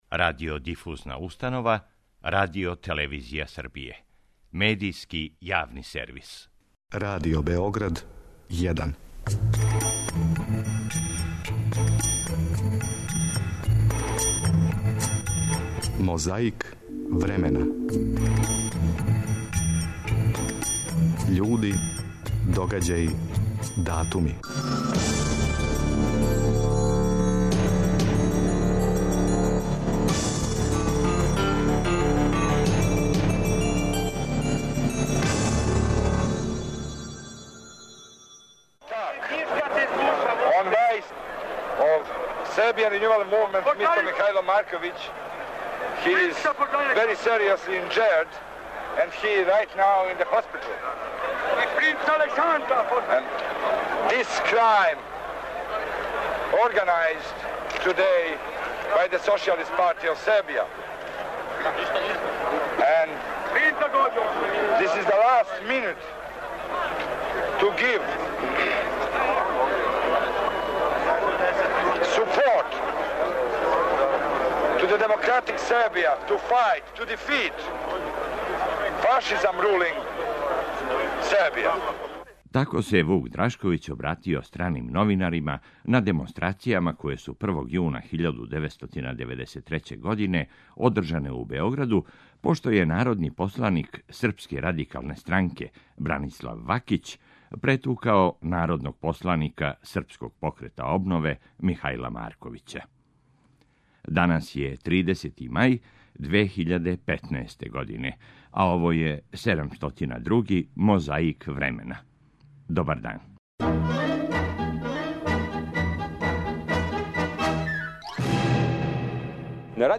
Звучна коцкица нас подсећа како се Вук Драшковић обратио страним новинарима на демонстрацијама које су 1. јуна 1993. године одржане у Београду пошто је народни посланик Српске радикалне странке, Бранислав Вакић претукао народног посланика Српског покрета обнове, Михајла Марковића.
Подсећа на прошлост (културну, историјску, политичку, спортску и сваку другу) уз помоћ материјала из Тонског архива, Документације и библиотеке Радио Београда.